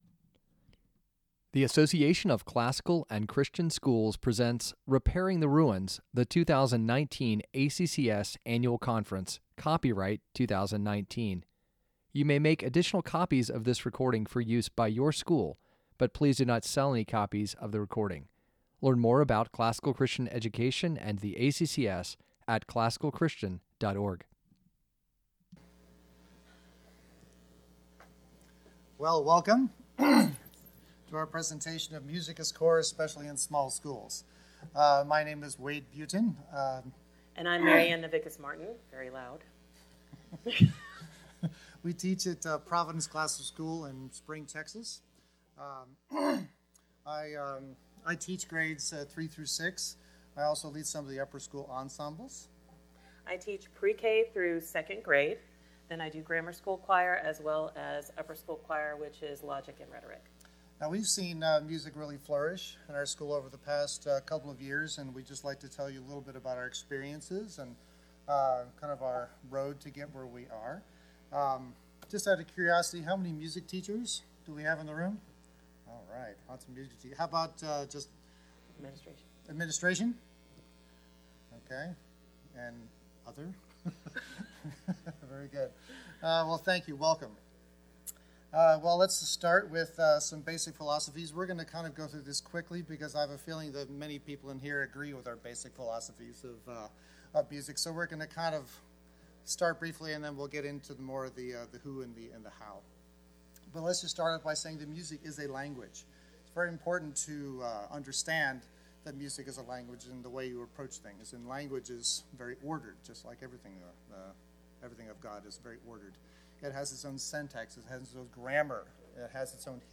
2019 Workshop Talk | 01:00:14 | All Grade Levels, Art & Music